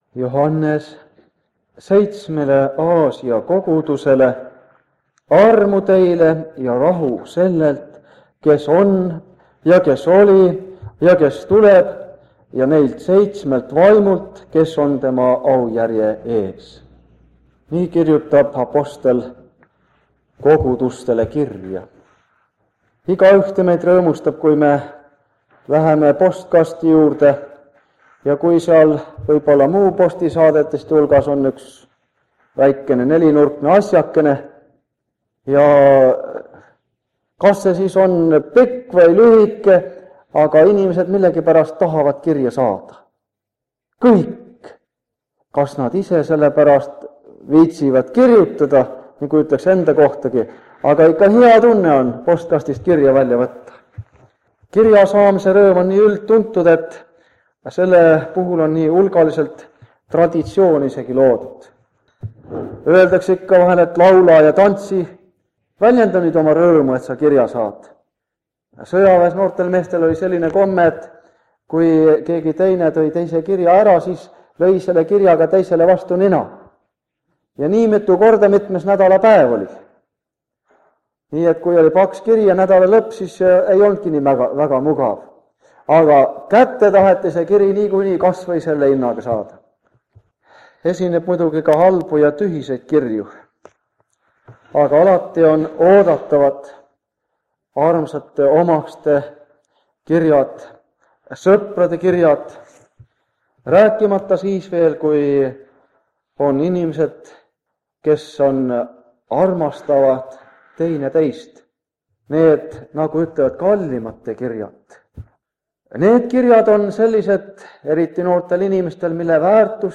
Ilmutuse raamatu seeriakoosolekud Kingissepa linna adventkoguduses